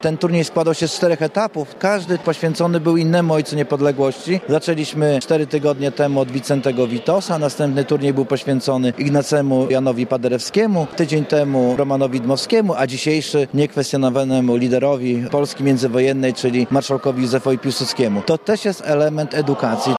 – Walki o utrzymanie niepodległości trwały jeszcze kilka lat po 1918 roku, dlatego kontynuujemy upamiętnianie rocznicy – mówi poseł Tomasz Zieliński: – Ten turniej składał się z czterech etapów.